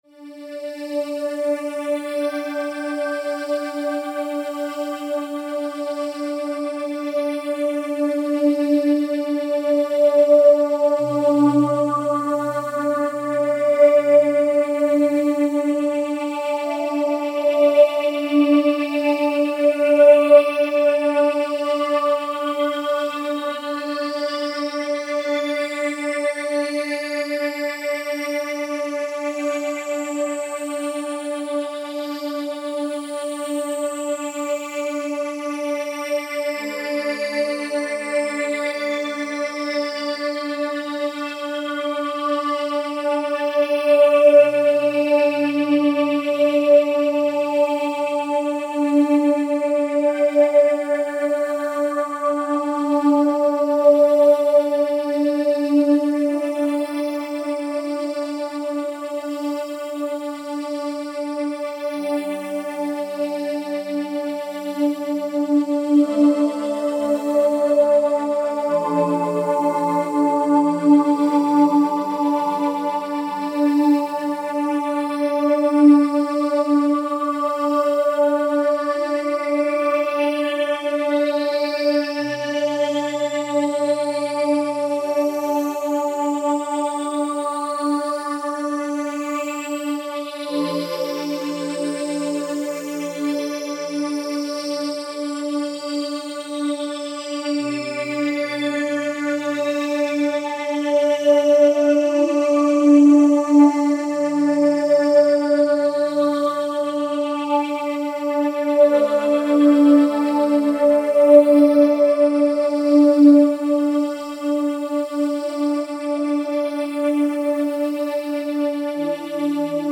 REIKI MUSIC
reiki-healing-spheres-sound.mp3